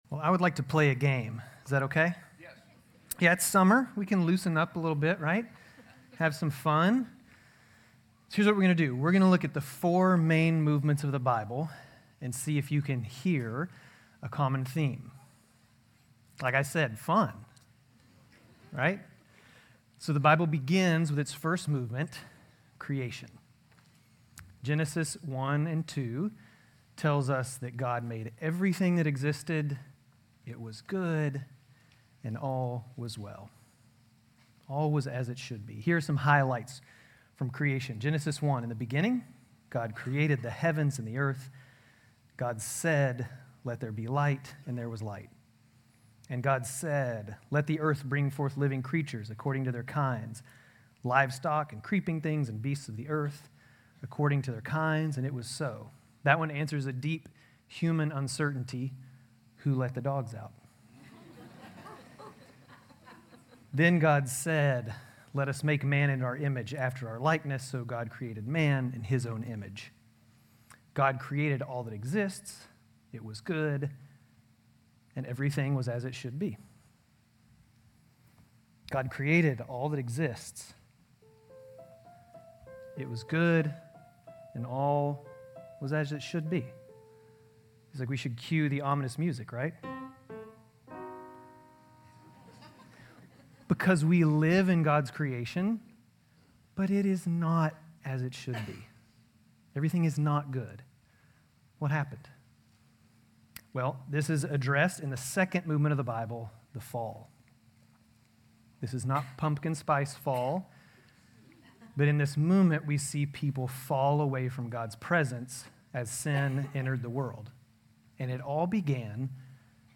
GCC-Lindale-July-9-Sermon.mp3